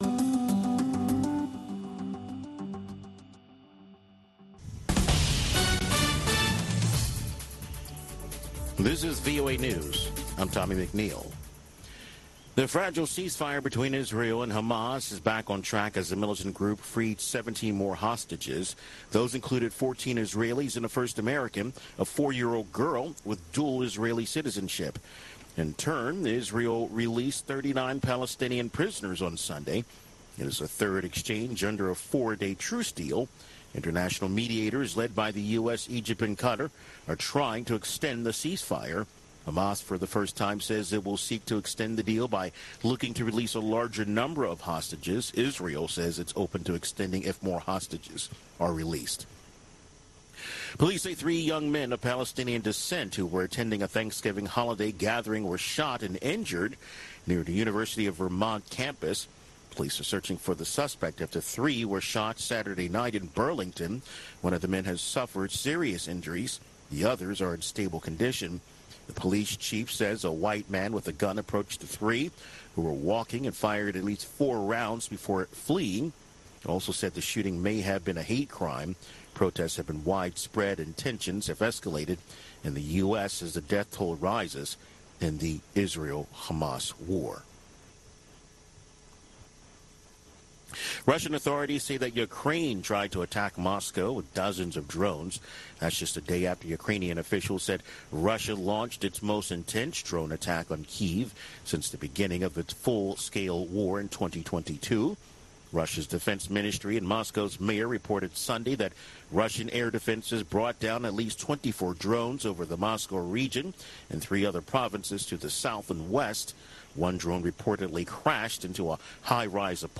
Two-Minute Newscast